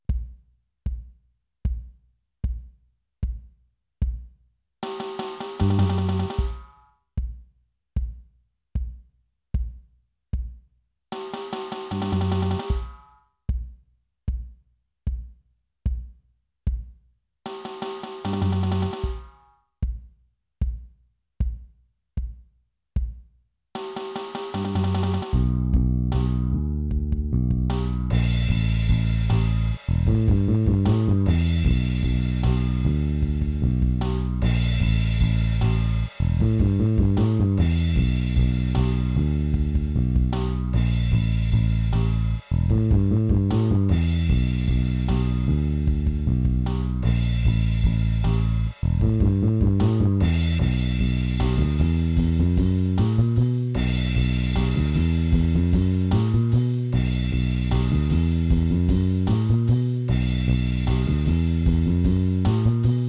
To help a bit I have included an audio file above that you can practice and play along with when you are ready.
SOLO
copy of iron_man solo.wav